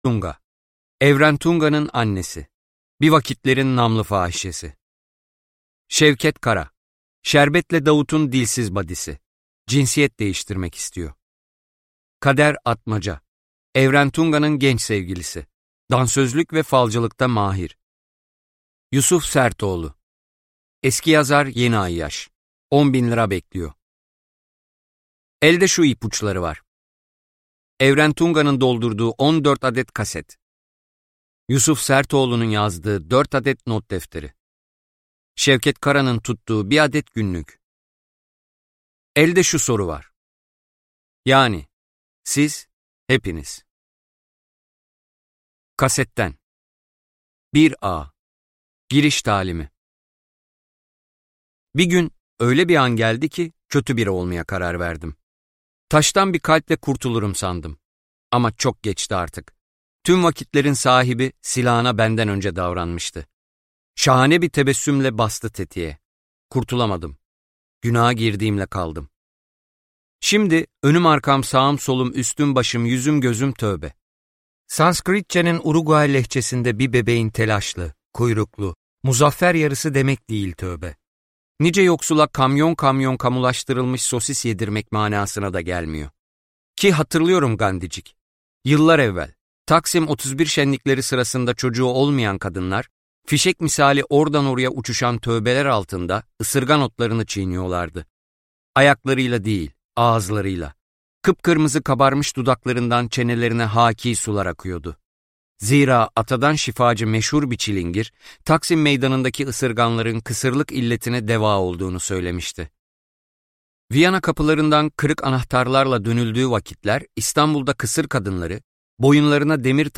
Merhume - Seslenen Kitap